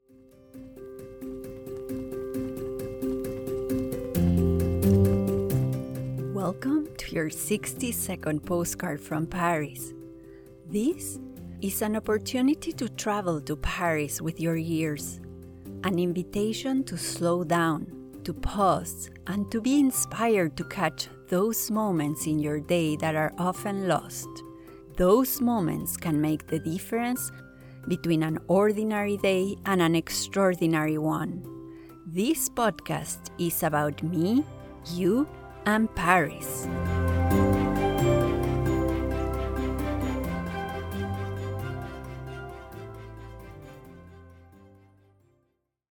The sound of each postcard brings alive a particular moment of the every day life in Paris. This is an opportunity to travel to Paris with your ears, take a deep breath and slow down to appreciate the good moments in your day.